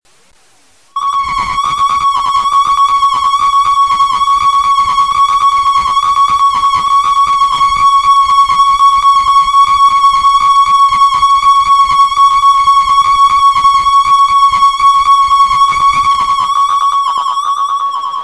Weird Feeback
This is the "Goblin" patch. It's fairly typical of the results. The chirpy effect is noticeable when the note is released, which leads me to believe the delay echoes are essential.